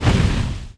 sound / monster2 / monkey / attack_eff_1.wav
attack_eff_1.wav